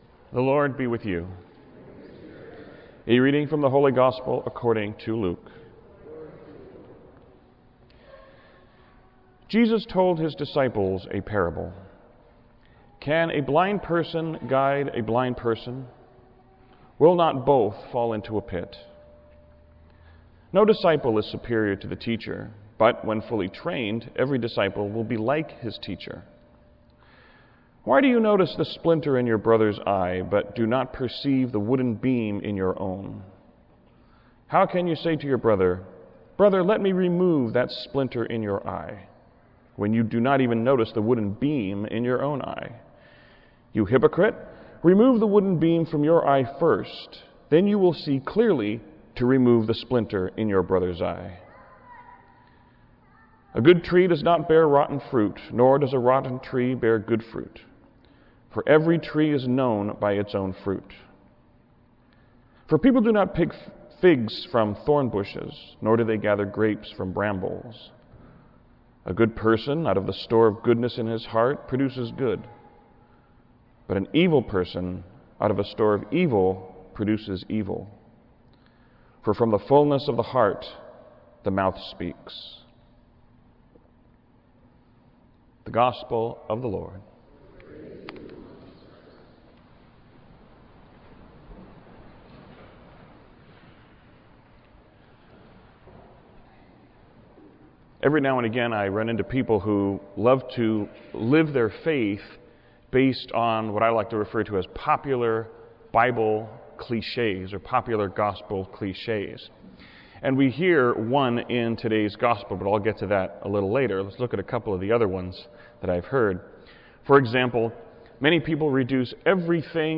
Homily-8thSundayCSpeck_PlankLent.wav